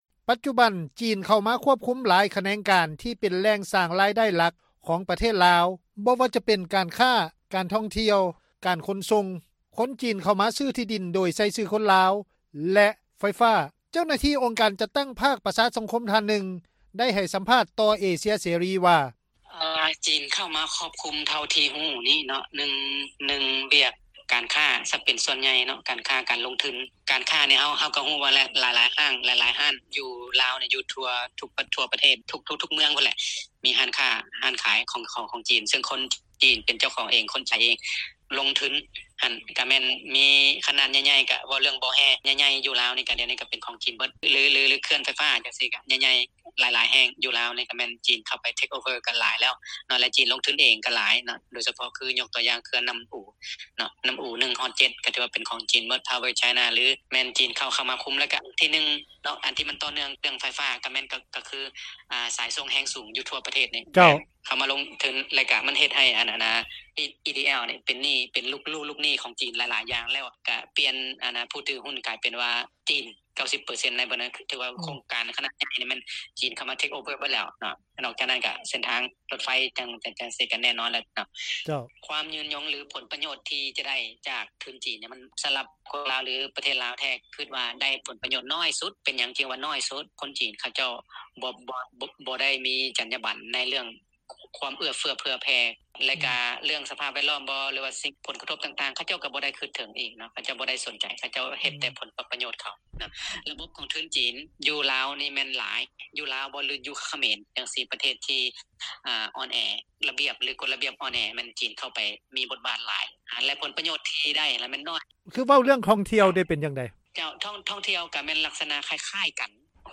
ເຈົ້າໜ້າທີ່ ອົງການຈັດຕັ້ງພາກປະຊາສັງຄົມລາວ ທ່ານນຶ່ງ ໄດ້ໃຫ້ສັມພາດຕໍ່ວິທຍຸ ເອເຊັຽເສຣີ ວ່າ: